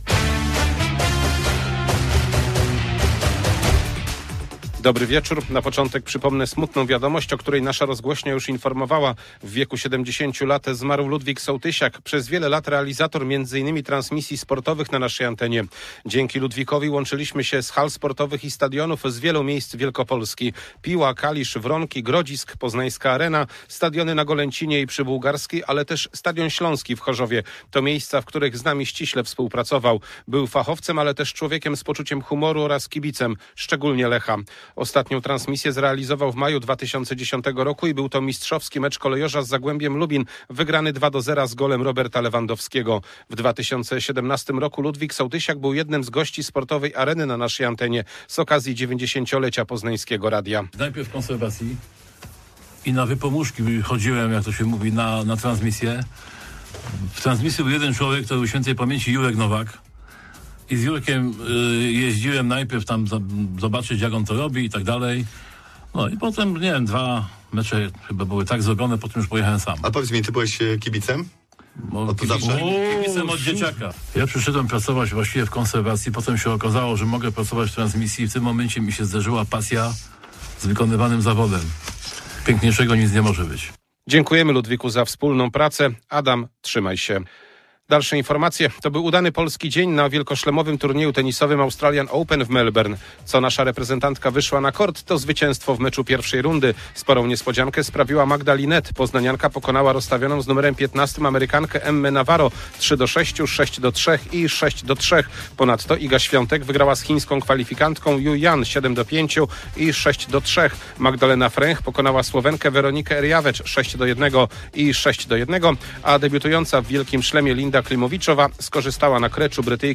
19.01.2026 SERWIS SPORTOWY GODZ. 19:05